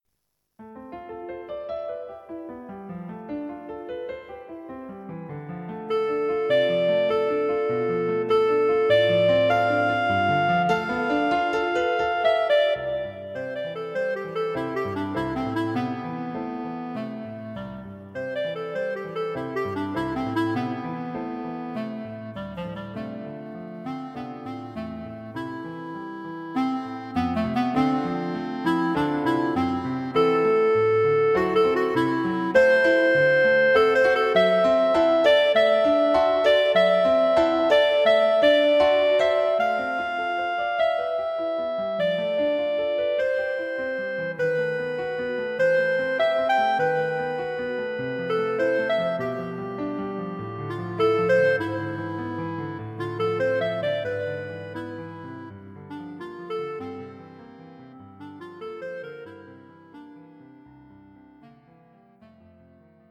Clarinet and Piano
This famous melody is arranged here for Clarinet and Piano.